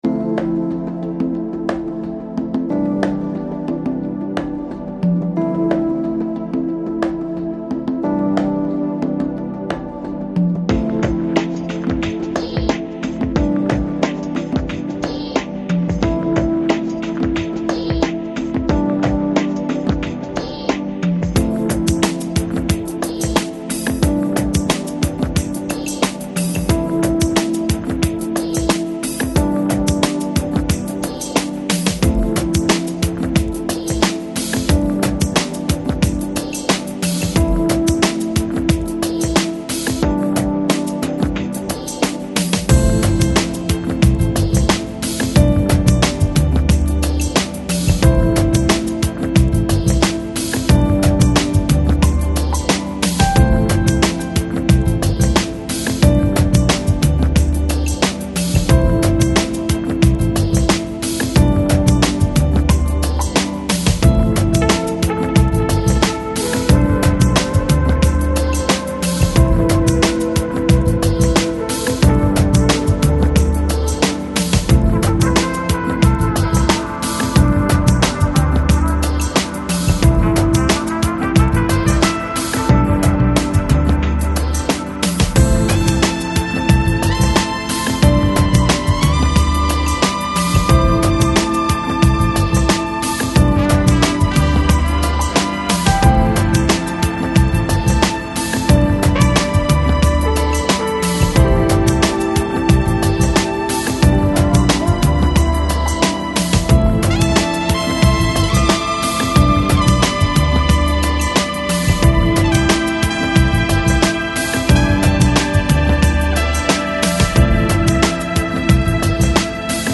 Жанр: Electronic, Chill House, Balearic, Downtempo